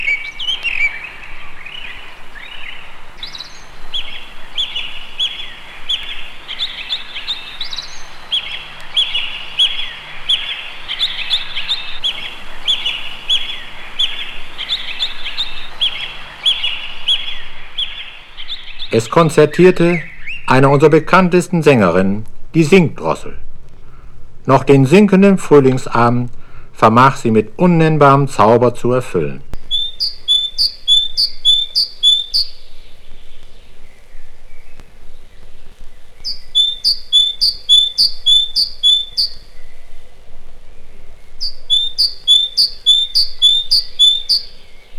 彼の研究の一端を資料化、本人の解説がとベルリンやミュンヘンでフィールドレコーディングして採集された様々な野鳥の声が収録された1枚。
Other, Non-Music, Field Recording　Germany　12inchレコード　33rpm　Stereo